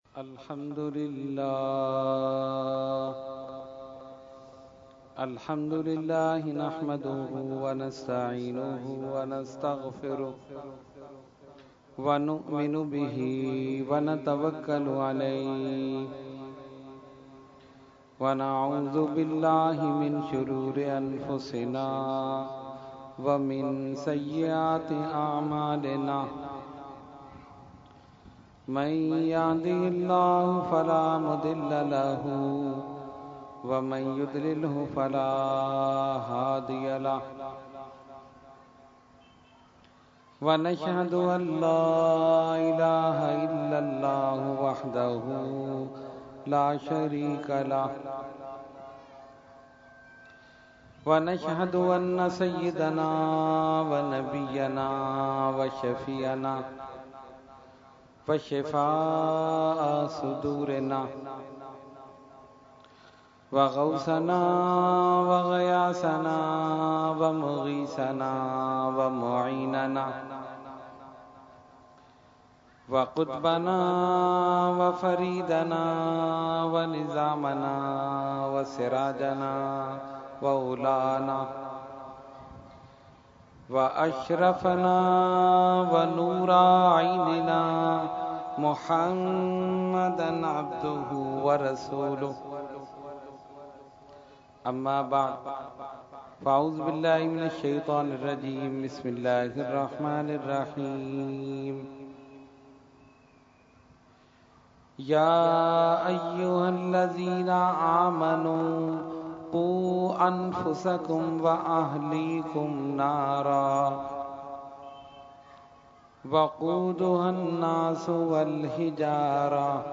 Category : Speech | Language : UrduEvent : Muharram 2016